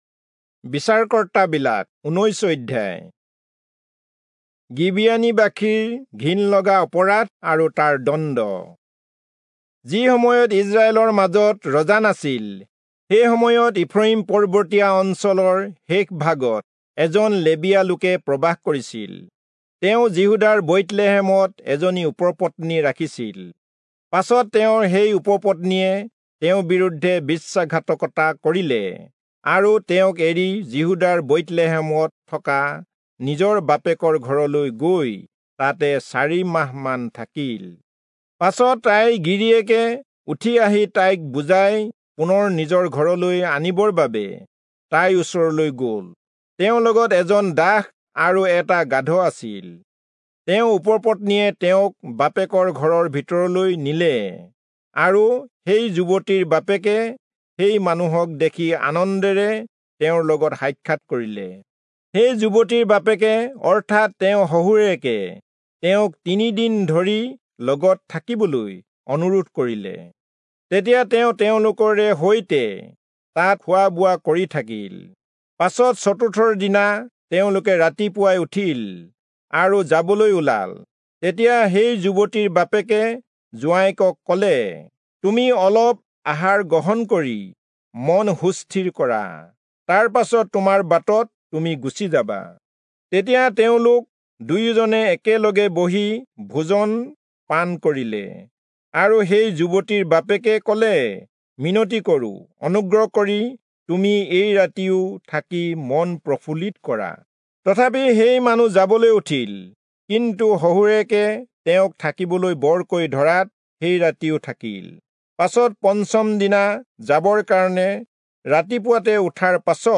Assamese Audio Bible - Judges 1 in Gntbrp bible version